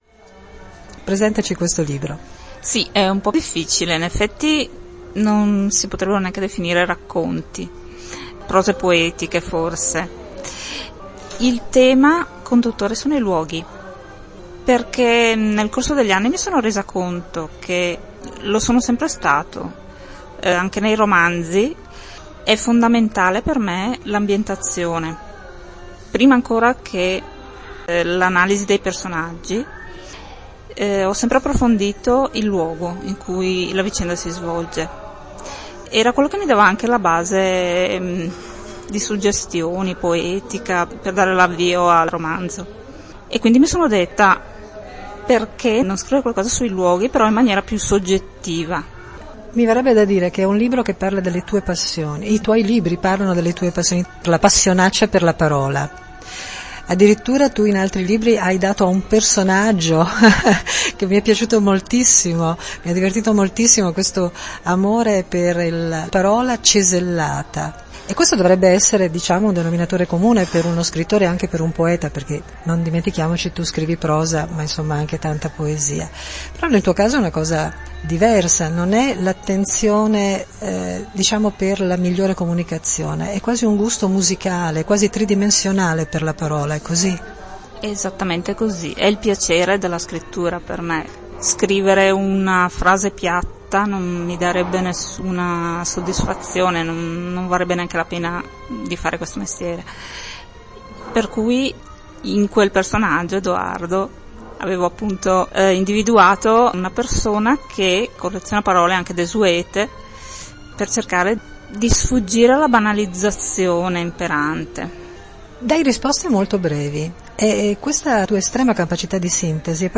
Ecco l’intervista